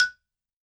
52-prc13-bala-f#4.wav